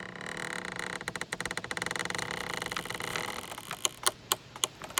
coffin.ogg